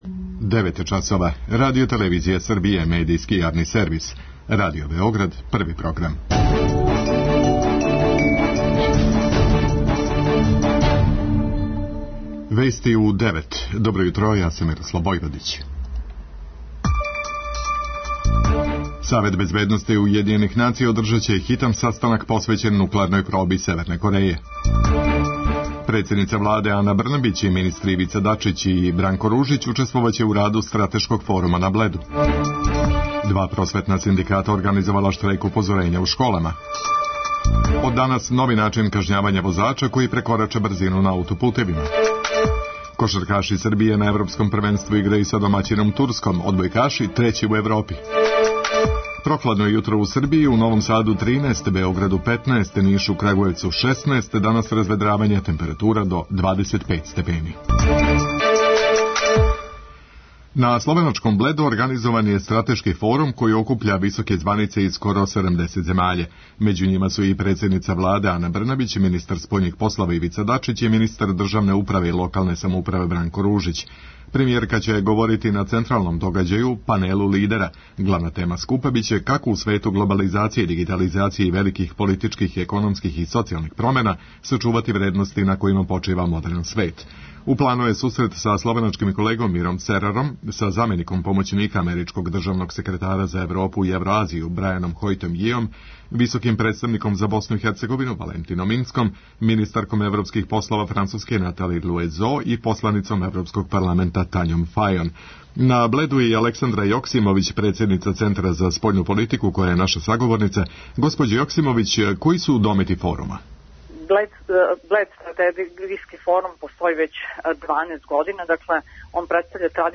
преузми : 4.10 MB Вести у 9 Autor: разни аутори Преглед најважнијиx информација из земље из света.